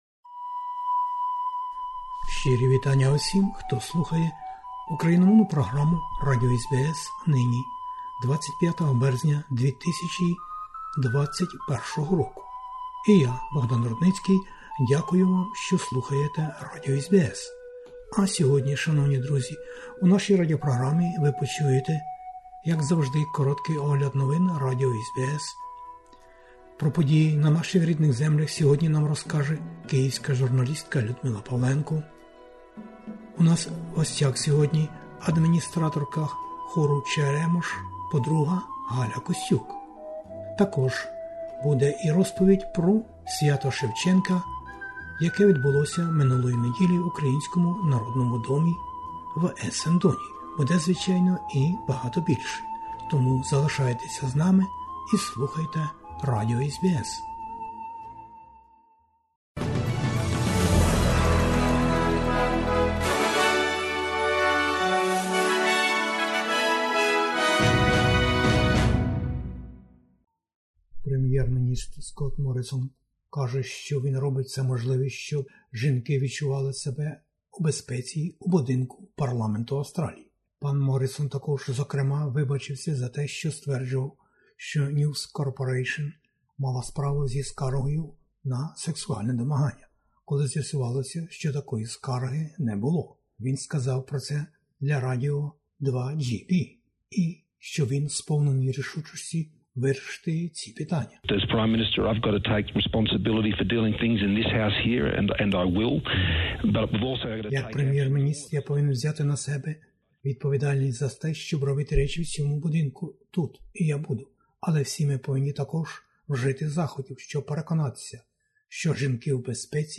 SBS НОВИНИ УКРАЇНСЬКОЮ